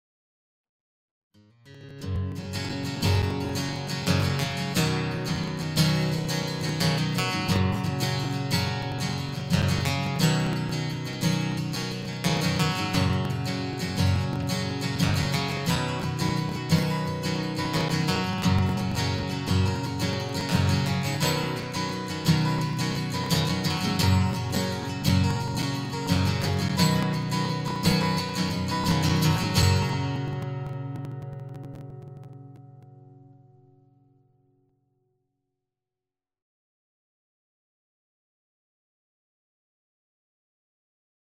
The Cannonbal acoustic guitar theme (mp3)